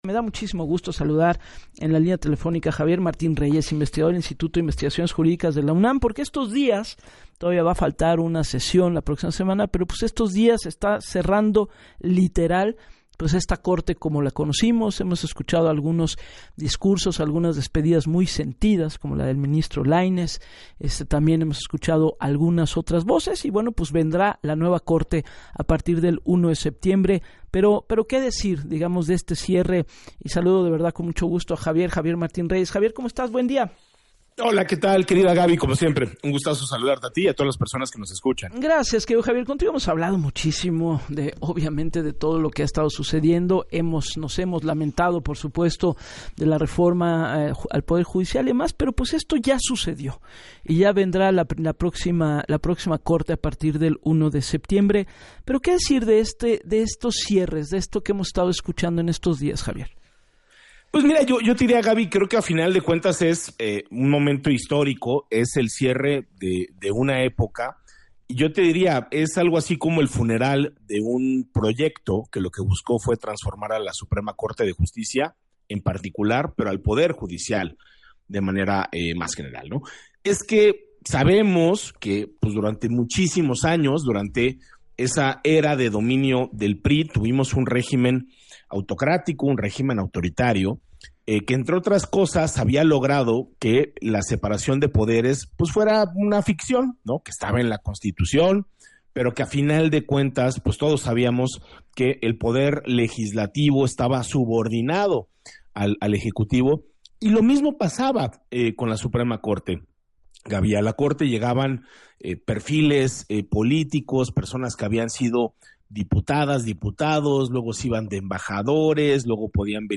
En entrevista para Así las Cosas con Gabriela Warkentin, calificó esta etapa como “el funeral de un proyecto que buscaba transformar la Corte y, en general, al Poder Judicial".